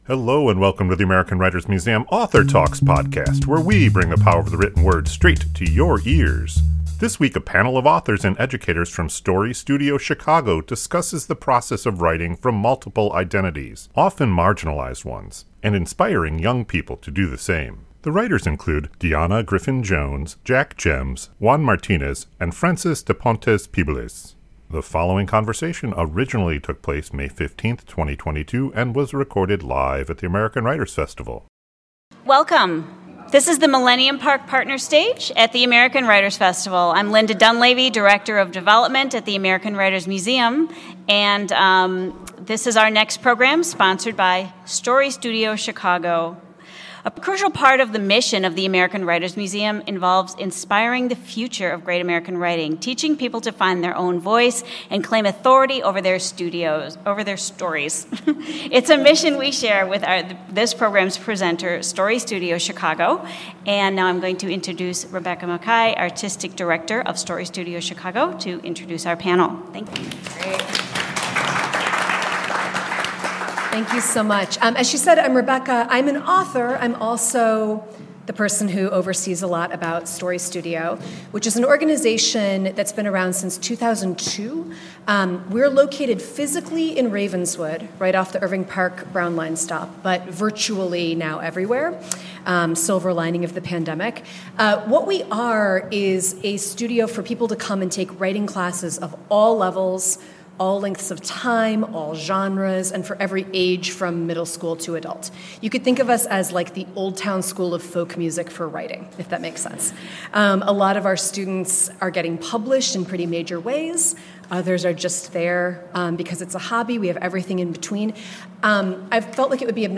This week, a panel of authors and educators from StoryStudio Chicago discuss the process of writing from multiple identities, often marginalized ones, and inspiring young people to do the same.